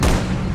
firemg.ogg